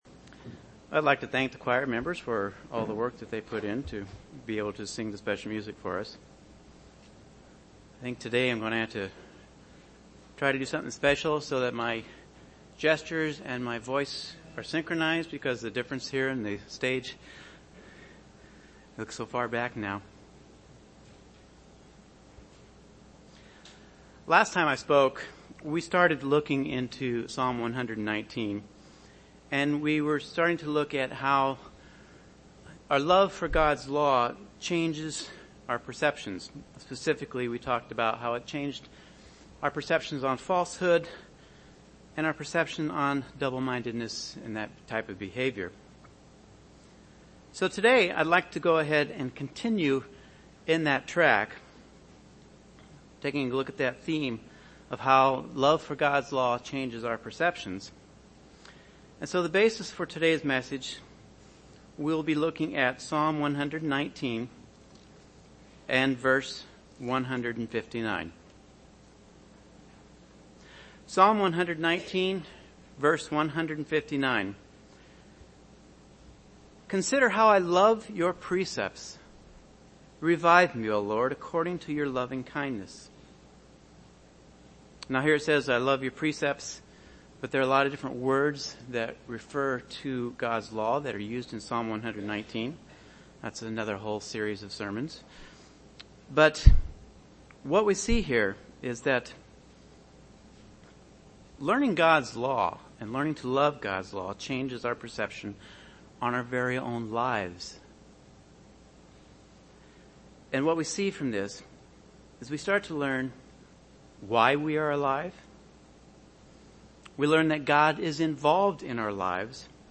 UCG Sermon Studying the bible?
Given in Chicago, IL